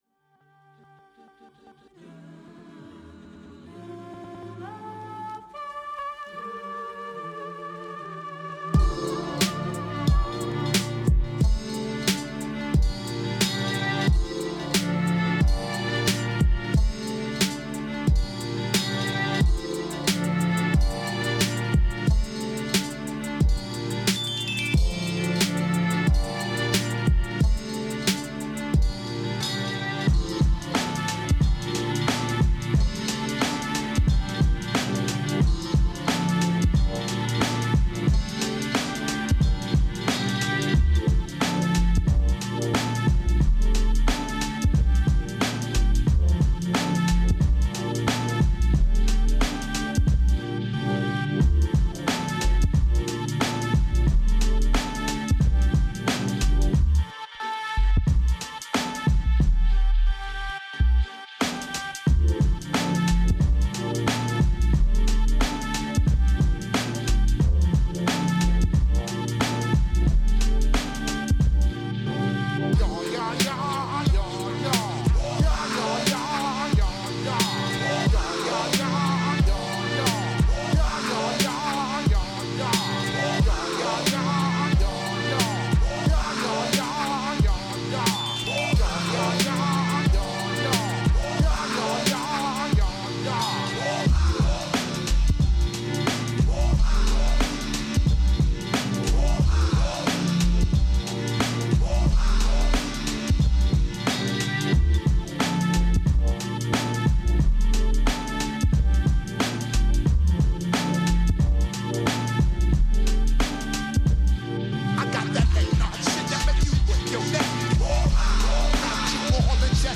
Beats Hip Hop